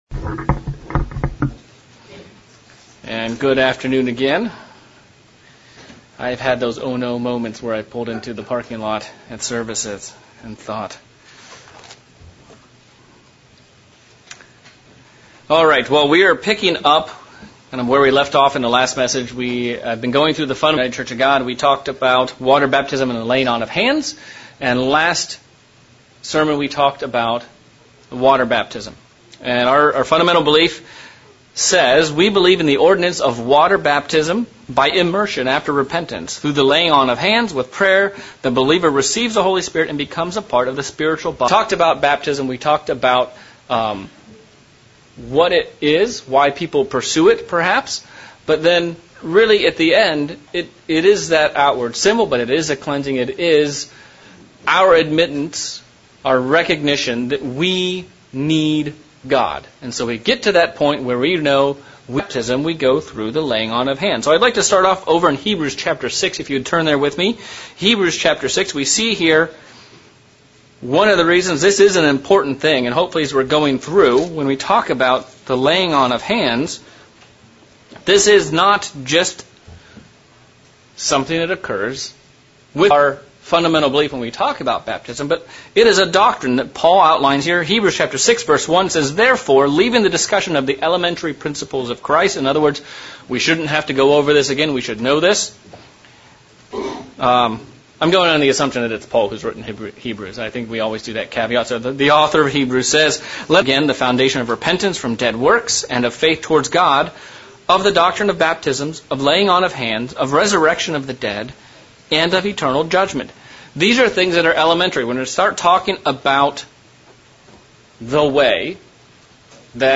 Sermon looking at the second part of the baptism ceremony, the laying on of hands and why it is important to the plan of God. Looking at how this function was used throughout the scriptures to set men apart for the work of God.